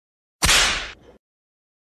Johnny Test Whip Crack Sound Effect Free Download
Johnny Test Whip Crack